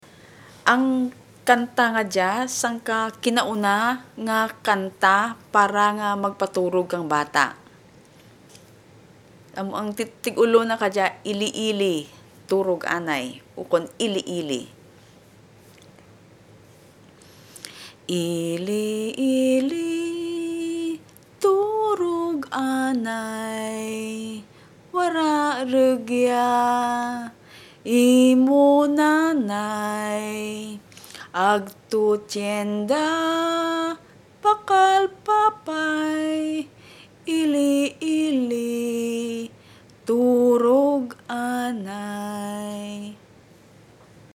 Ili-ili Lullaby